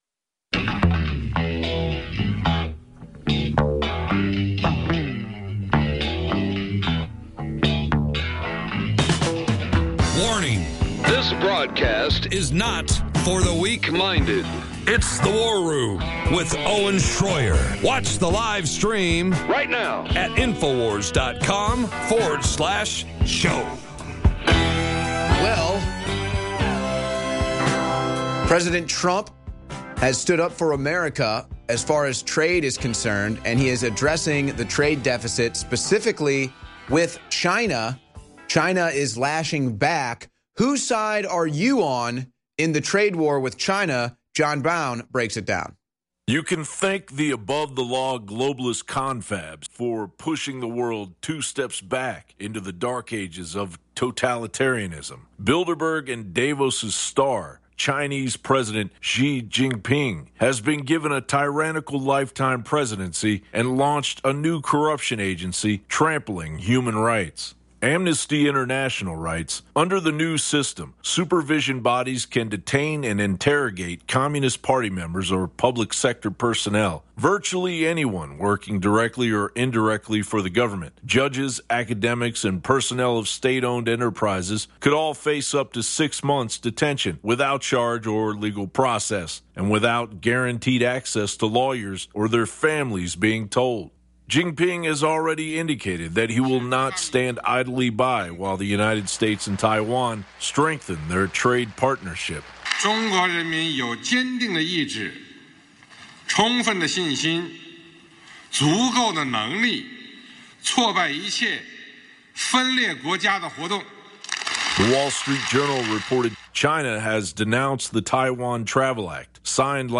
President Trump has just signed the new budget deal which includes funding to Planned Parenthood, as well as increases to congress's budget and salaries while not addressing the necessities for a border wall. We take callers response to this to find out how Trump's base feels about the bill. We also cover news from Canada indication a gun confiscation may be on the horizon.